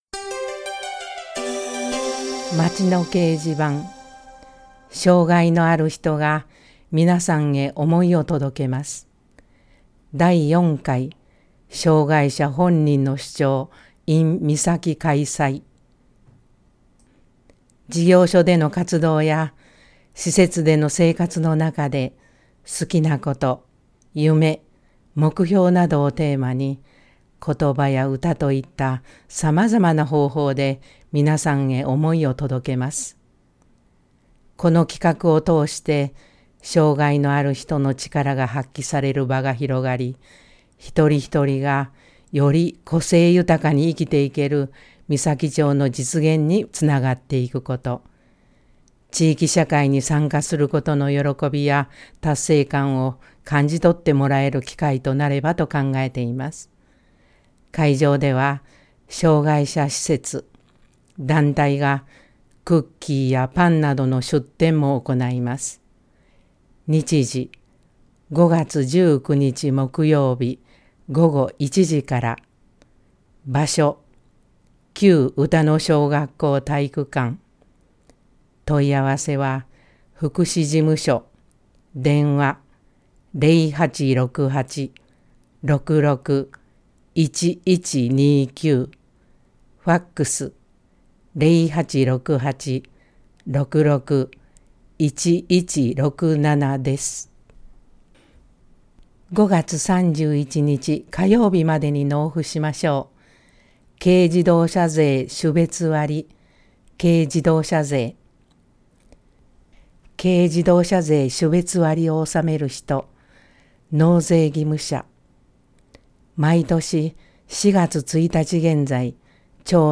広報誌の一部を読み上げています。